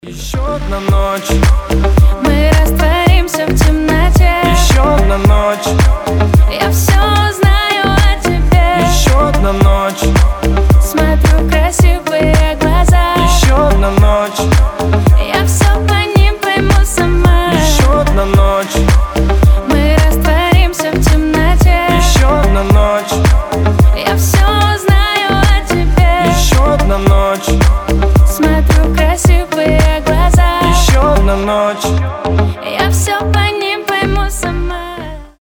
• Качество: 320, Stereo
поп
deep house
дуэт
женский и мужской вокал